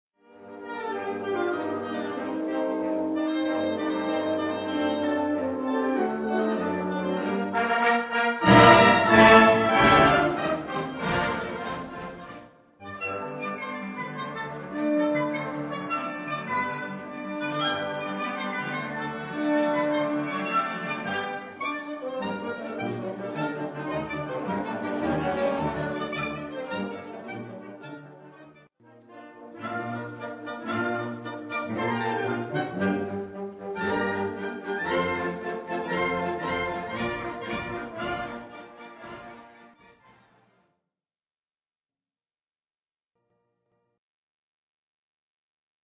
Gattung: Konzertant
Besetzung: Blasorchester